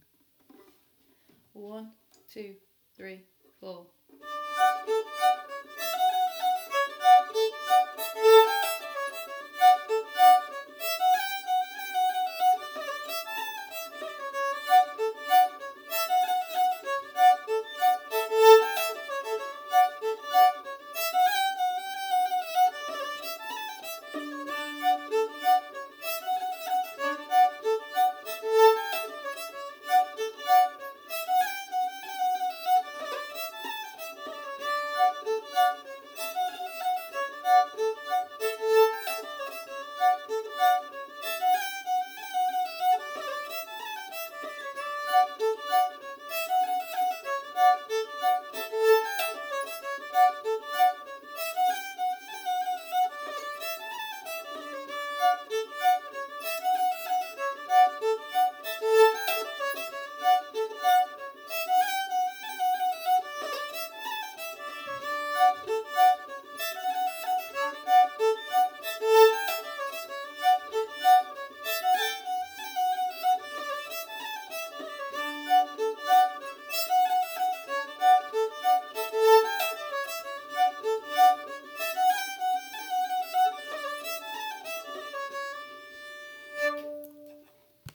B Part Only